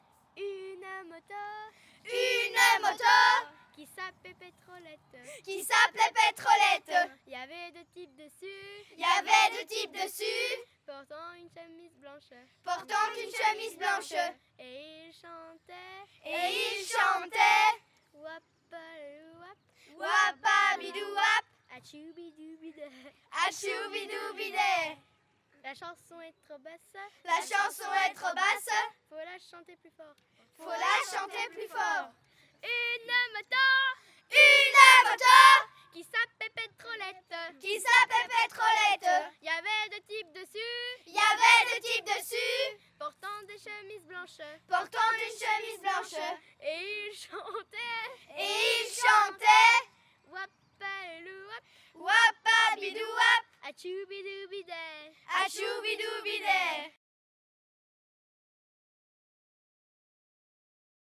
Genre : chant
Type : chant de mouvement de jeunesse
Interprète(s) : Patro de Pontaury
Lieu d'enregistrement : Florennes
Il s'agit d'un chant à "répétition" : une personne le lance et les autres répètent. C'est également un chant qui se chante de plus en plus fort. Il est souvent interprété en marchant pour se donner du courage pendant l'effort.